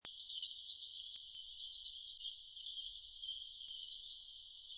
62-1紅尾伯勞2衛武營2012apr1.WAV
紅尾伯勞(普通亞種) Lanius cristatus lucionensis
錄音地點 高雄市 鳳山區 衛武營
錄音環境 公園樹上
行為描述 鳴叫
錄音: 廠牌 Denon Portable IC Recorder 型號 DN-F20R 收音: 廠牌 Sennheiser 型號 ME 67